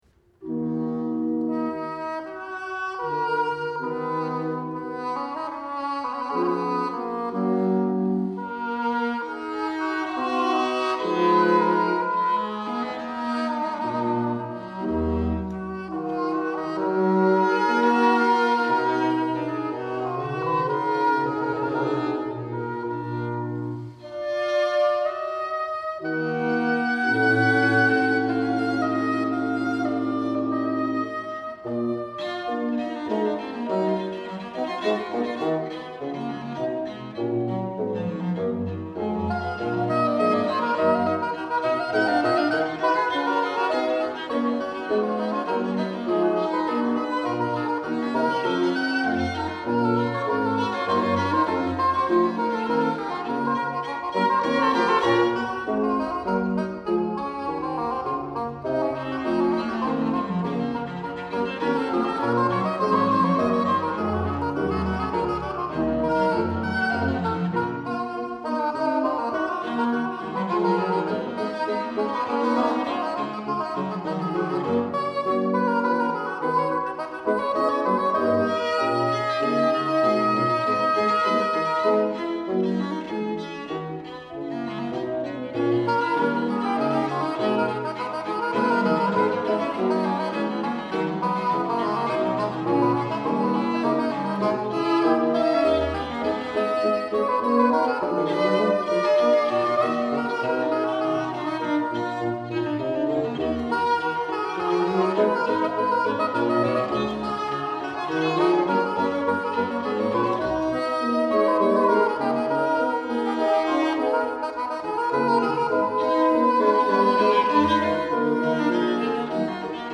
8. Seconde partie – Sinfonia
Hautbois d’amour, Viole de gambe, Continuo
08-8.-Seconde-partie-–-Sinfonia.mp3